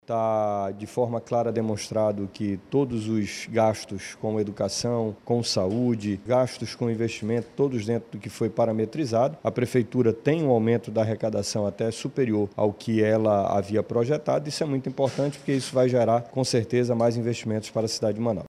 A sessão foi presidida pelo vereador Marcelo Serafim, do PSB, que ressaltou a importância do planejamento e transparência da administração pública.